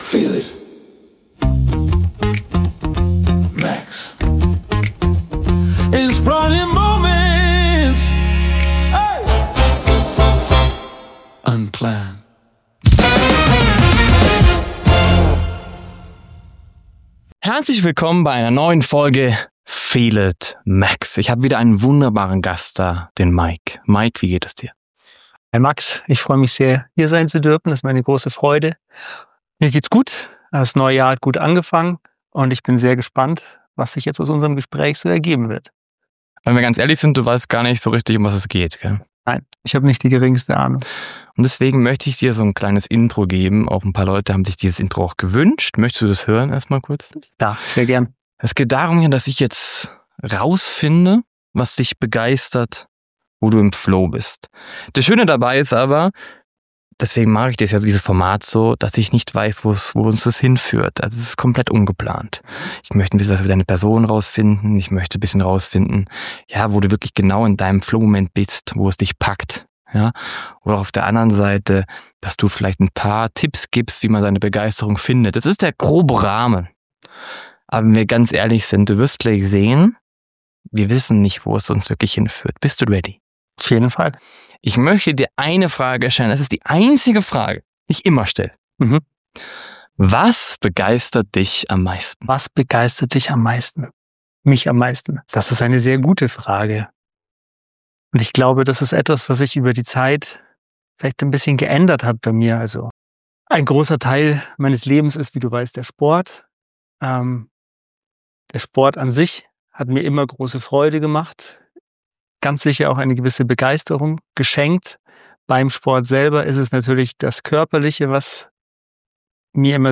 Und ganz nebenbei wurde viel gelacht und auch über den Sinn des Lebens philosophiert...